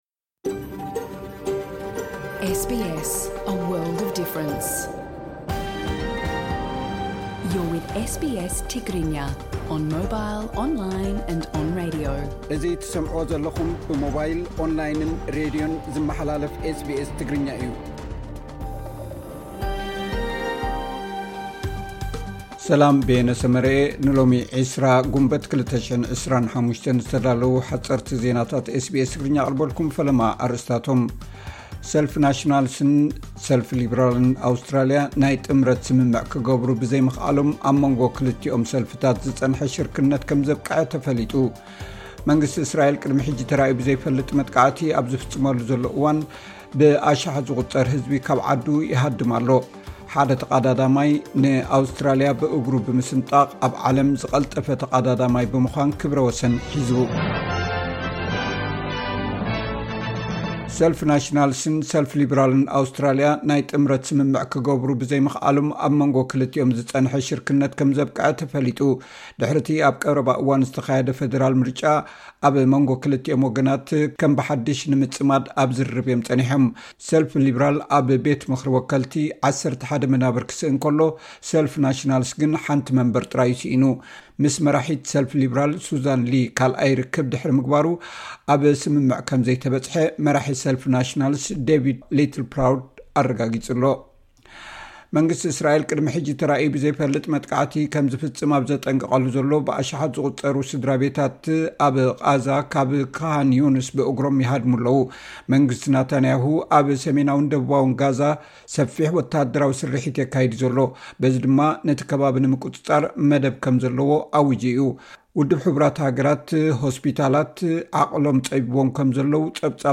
ሓጸርቲ ዜናታት ኤስ ቢ ኤስ ትግርኛ (20 ግንቦት 2025)